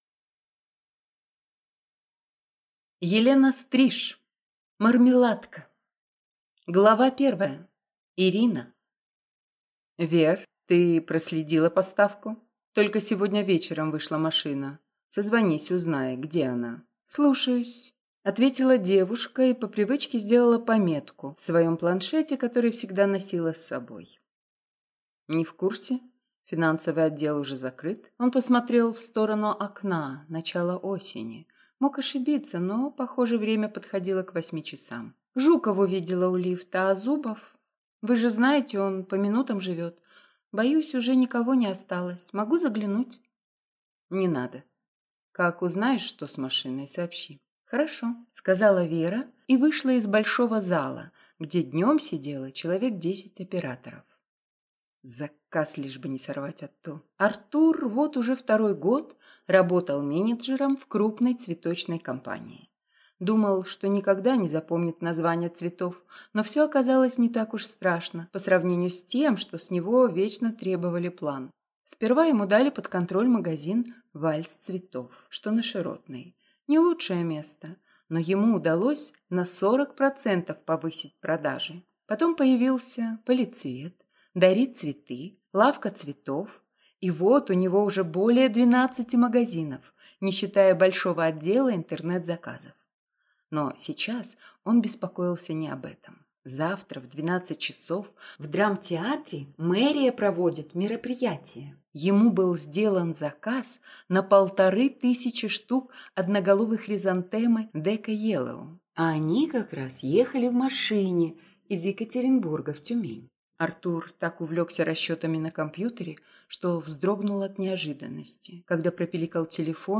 Аудиокнига Мармеладка | Библиотека аудиокниг